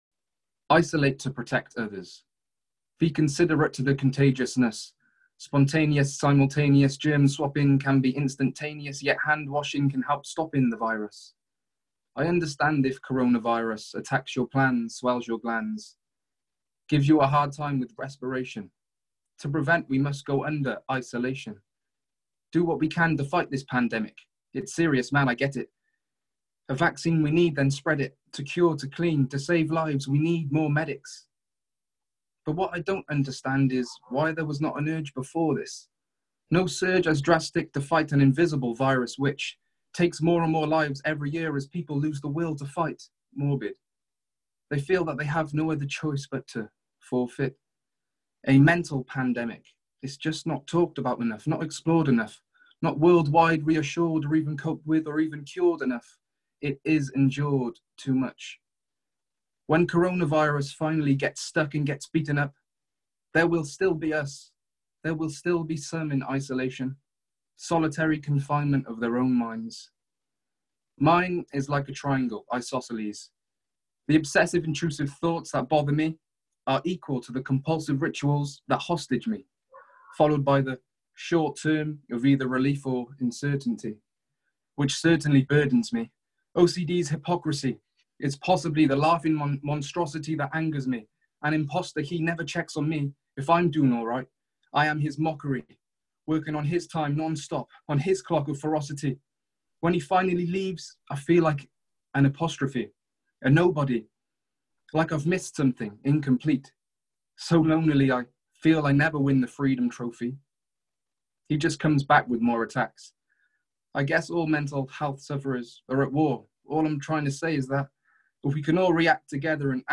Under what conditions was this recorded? The show took place on Zoom while the live shows were suspended because of Coronavirus.